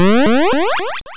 Attack sound effects are now in the folder of the type of their attack Pokémon flicker briefly when hit 2021-10-22 12:21:12 +02:00 202 KiB Raw Permalink History
Fly_Start.wav